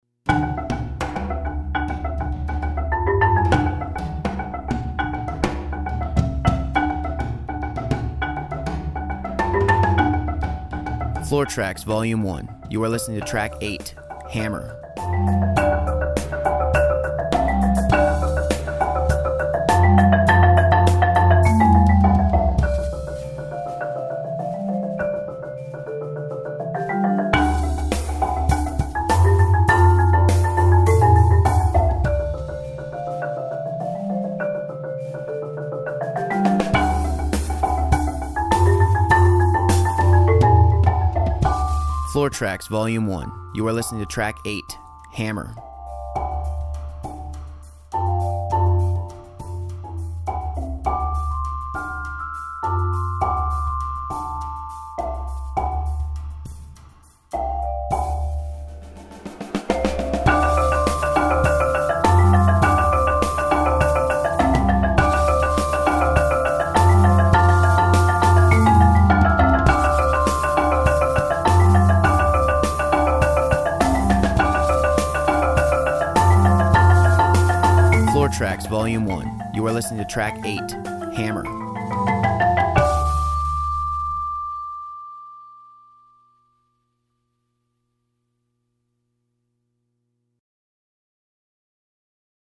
(with voiceover)   Purchase high-quality track